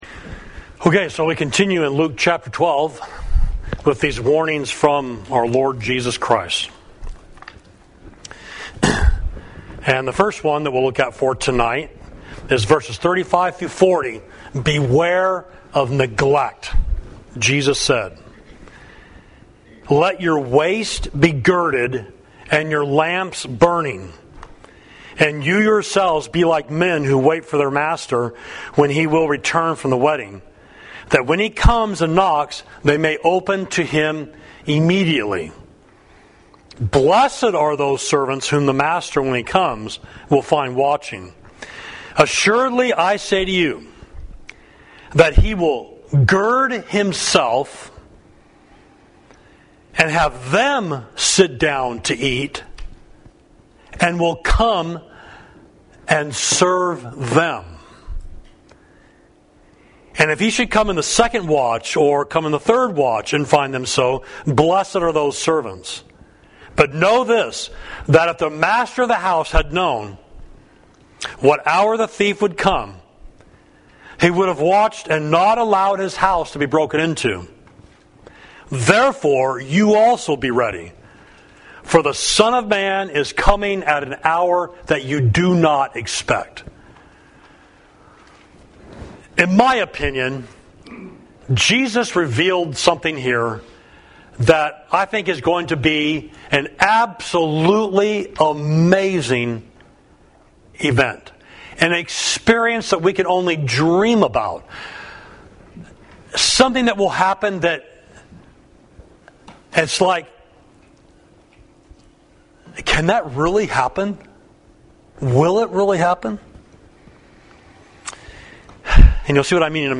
Sermon: Some Warnings from Jesus: Part 2, Luke 12.35–59